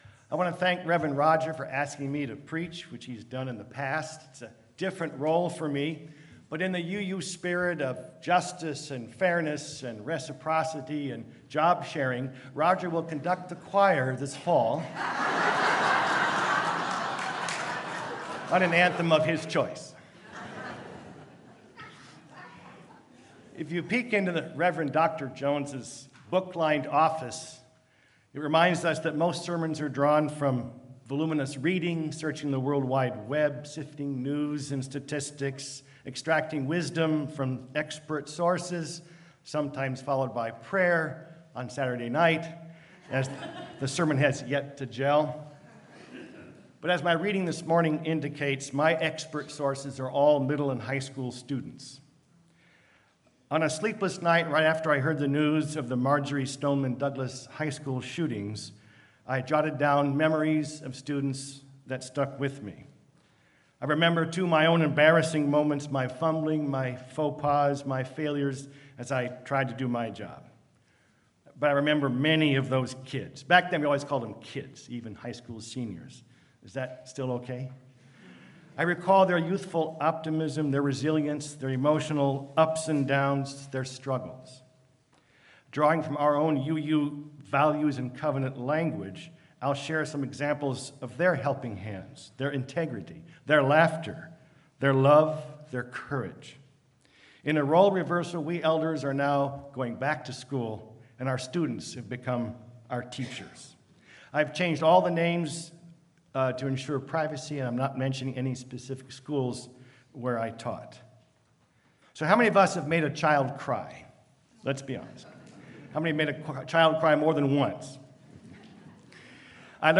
Sermon-What-My-Students-Taught-Me.mp3